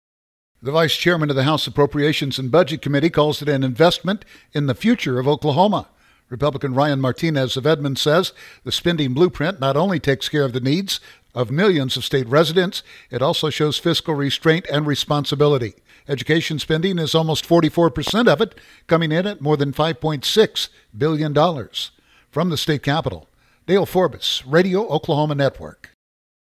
give details on the budget plan.